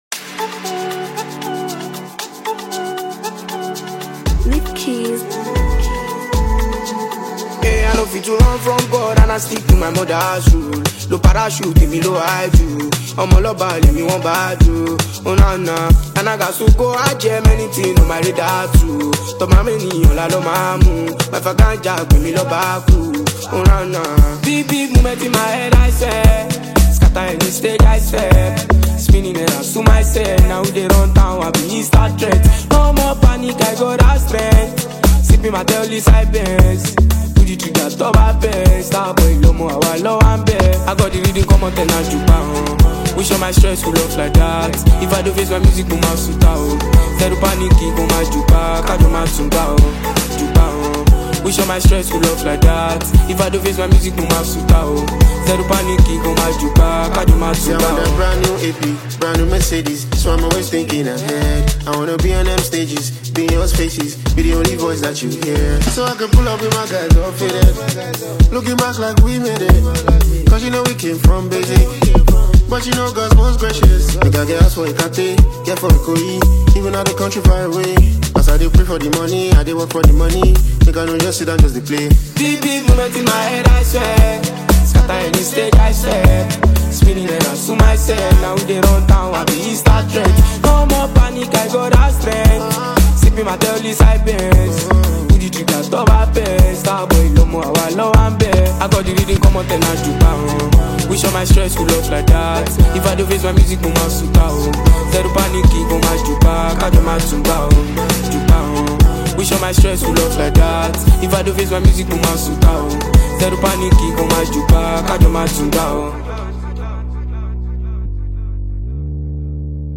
who smashed the banger anthem remarkably.